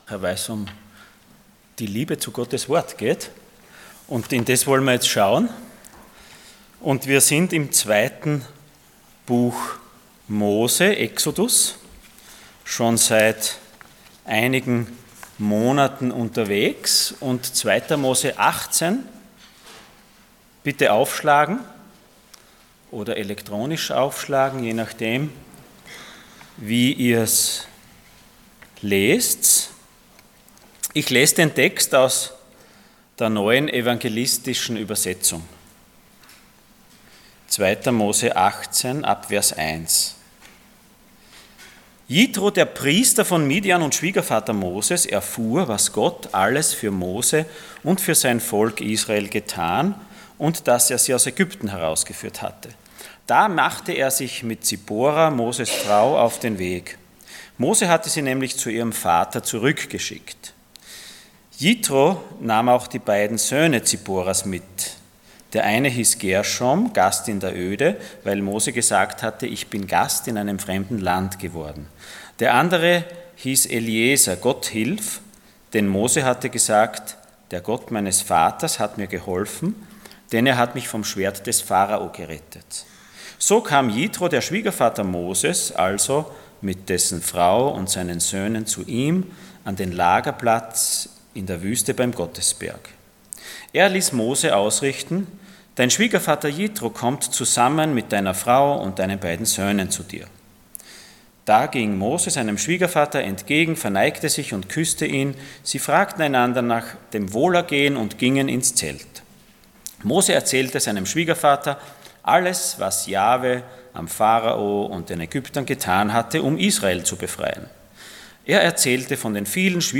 Mose 18 Dienstart: Sonntag Morgen Ein weiser Rat Themen: Gaben , Gemeinde , Hierarchie , Management , Ordnung , Rat « Gottvertrauen Die Gottesbegegnung – Wer wird das überleben?